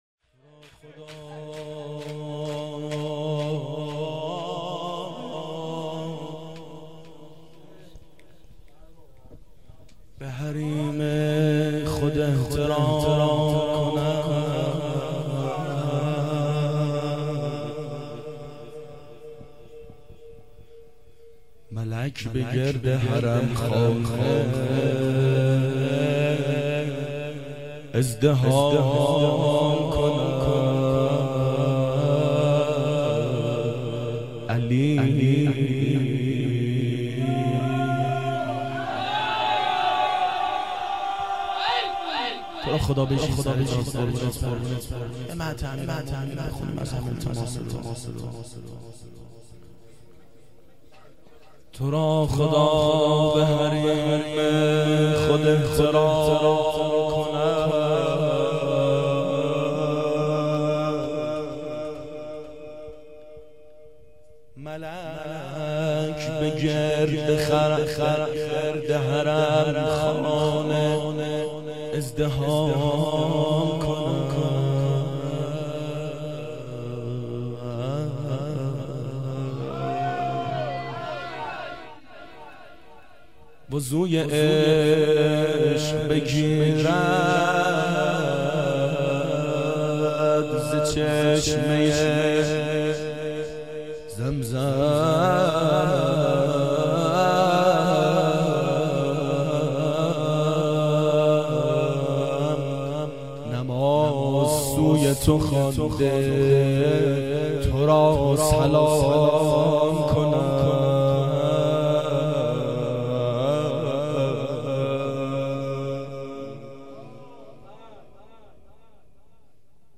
مدح زیبای امیرالمؤمنین علیه السلام
• گلچین سال 1389 هیئت شیفتگان حضرت رقیه سلام الله علیها